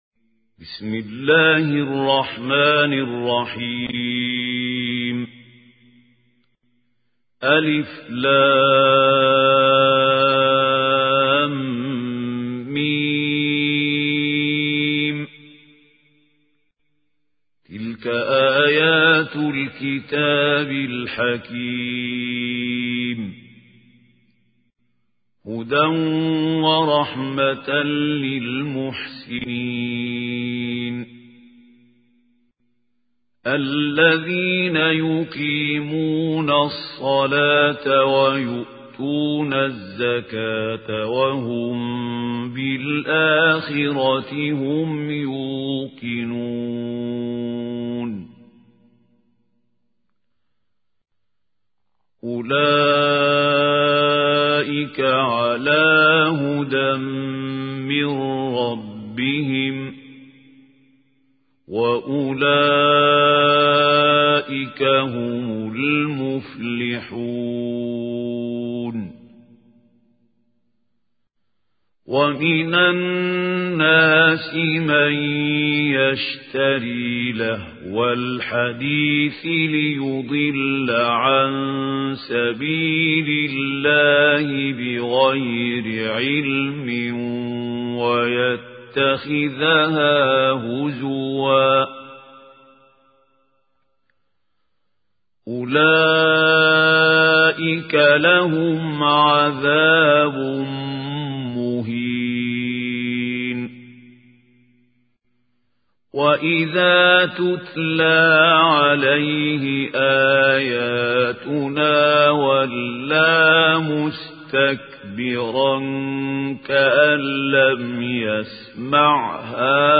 اسم التصنيف: المـكتبة الصــوتيه >> القرآن الكريم >> الشيخ خليل الحصري
القارئ: الشيخ خليل الحصري